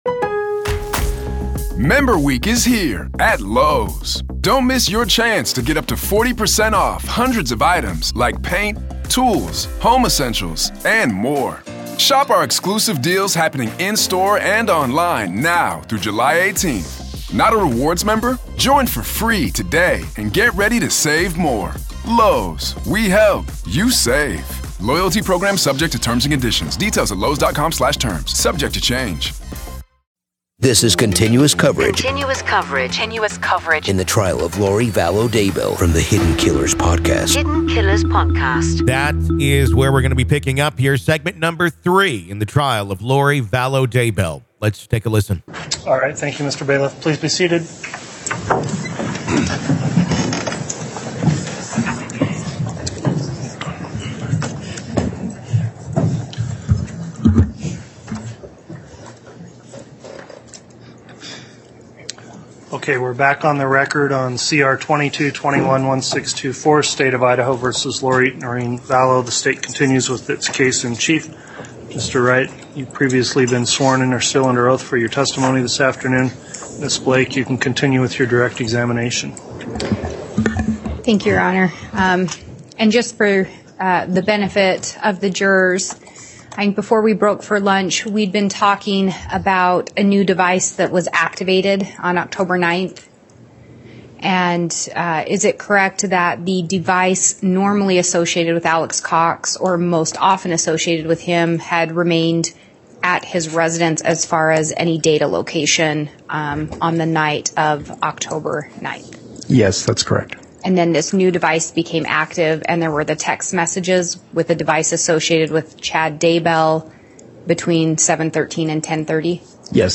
The Trial Of Lori Vallow Daybell Day 14 Part 3 | Raw Courtroom Audio